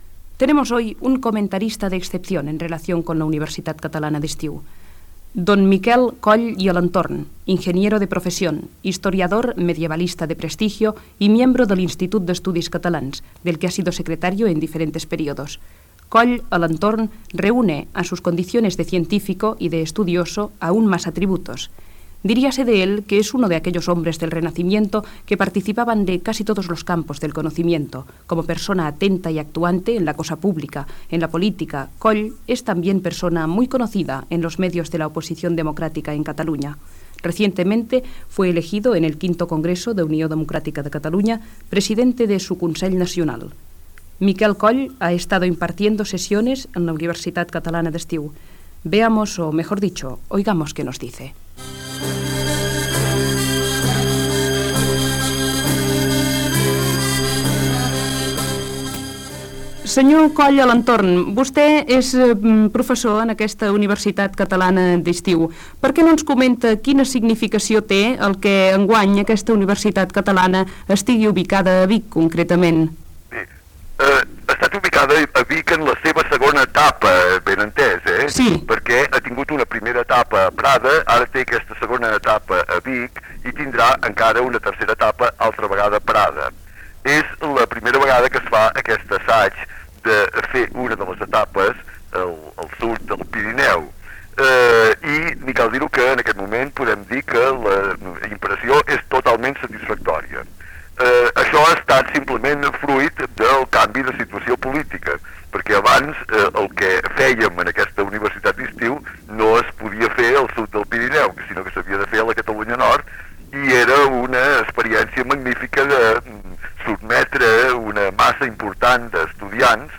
Entrevista a l'enginyer, medievalista i polític Miquel Coll i Alentorn sobre la Universitat Catalana d'Estiu que s'ha celebrat a Vic